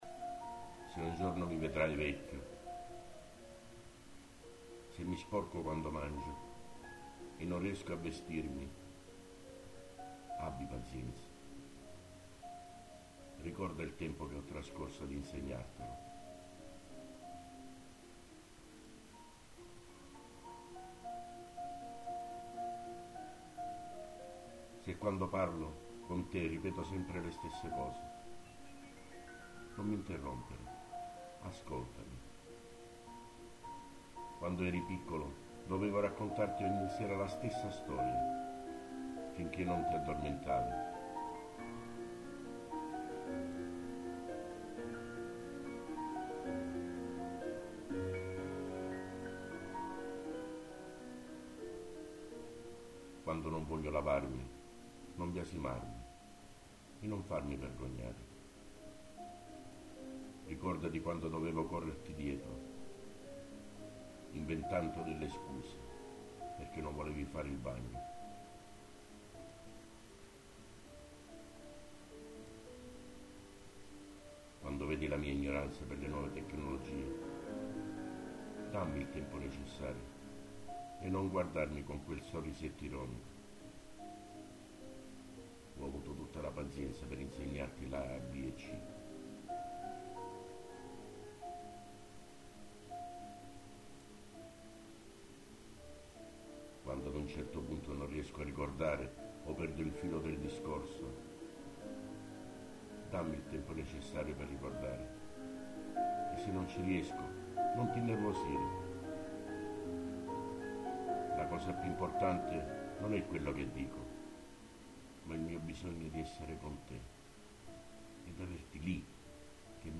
Poesia recitata. Lettera di un padre al figlio.